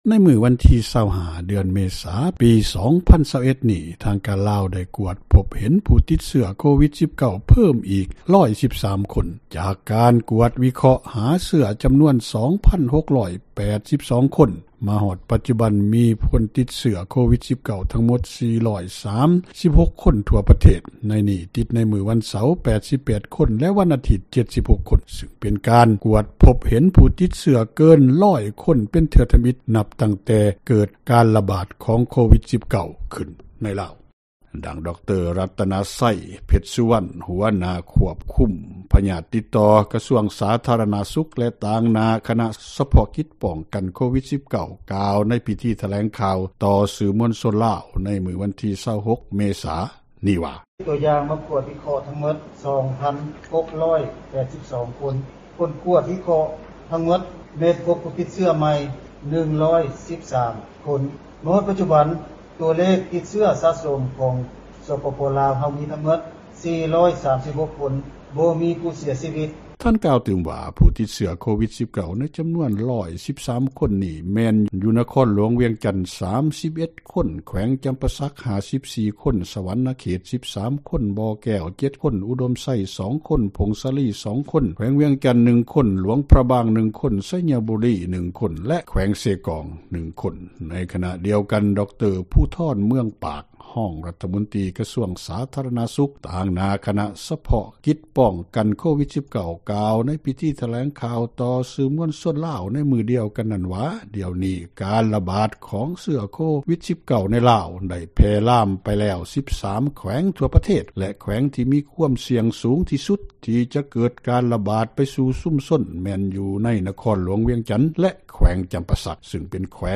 ດັ່ງ ດຣ. ຣັດຕະນະໄຊ ເພັດສຸວັນ ຫົວຫນ້າກົມ ຄວບຄຸມພຍາດຕິດຕໍ່ ກະຊວງສາທາຣະນະສຸຂ ແລະ ຕ່າງຫນ້າຄະນະ ສະເພາະກິດ ປ້ອງກັນໂຄວິດ-19 ກ່າວໃນພິທີຖແລງຂ່າວ ຕໍ່ສື່ມວລຊົນລາວ ໃນມື້ວັນທີ 26 ເມສາ ນີ້ວ່າ: